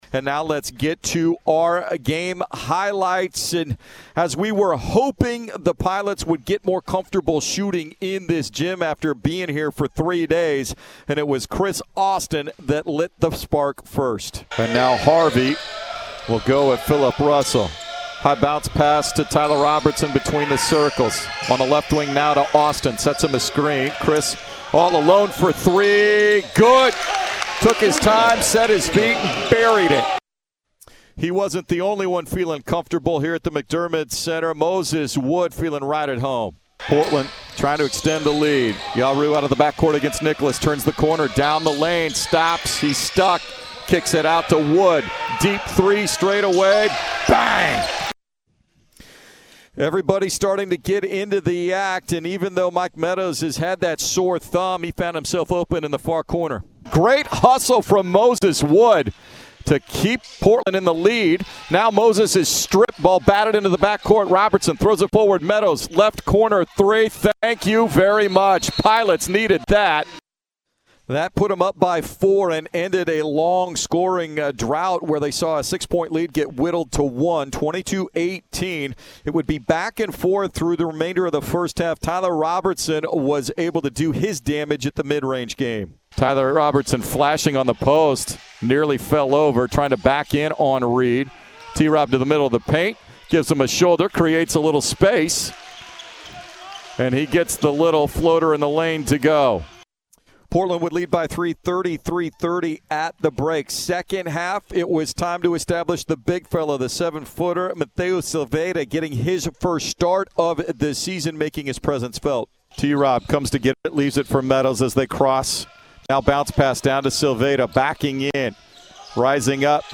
November 27, 2021 Radio highlights from Portland's 74-68 win over SEMO at the Incarnate Word Tournament.